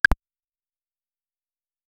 ui-pop-up-sound-bobble-g3r3prle.wav